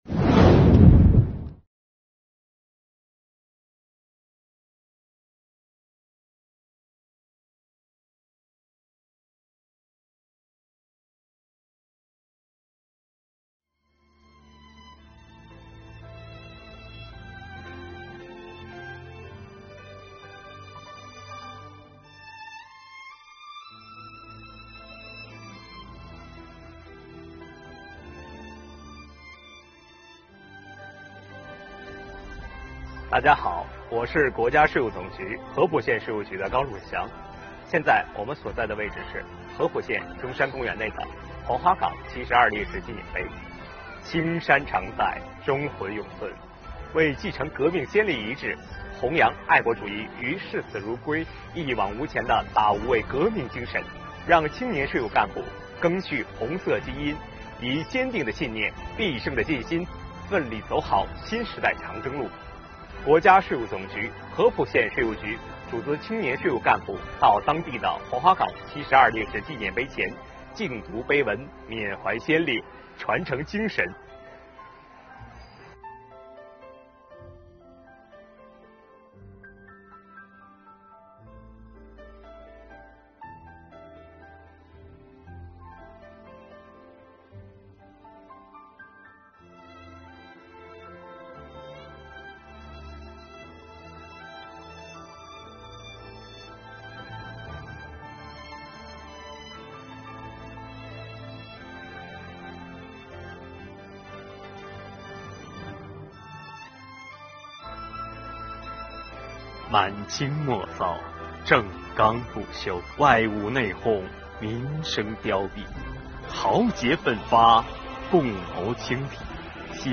开展纪念碑碑文敬读活动，正是其中一项很有意义的活动。
为继承革命先烈遗志，弘扬爱国主义与视死如归、一往无前的大无畏革命精神，让青年税务干部赓续红色基因，以坚定的信念、必胜的信心奋力走好新时代长征路，国家税务总局合浦县税务局组织青年干部到当地黄花岗七十二烈士纪念碑前，敬读碑文，缅怀先烈，传承精神。